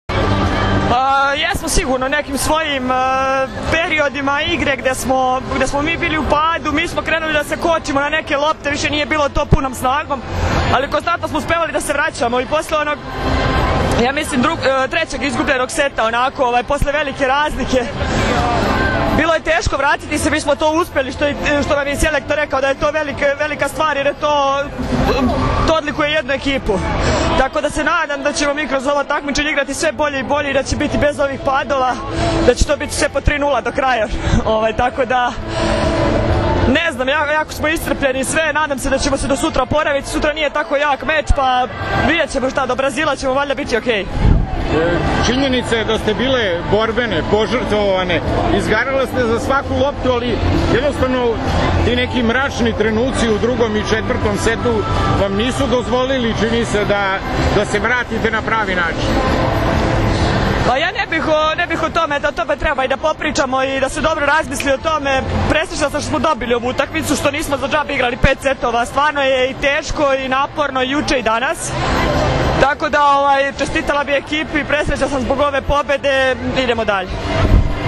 IZJAVA NATAŠE KRSMANOVIĆ